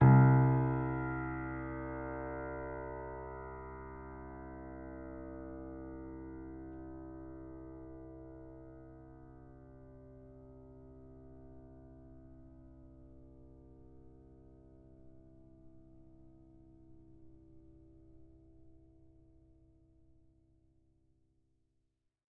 sampler example using salamander grand piano
C2.ogg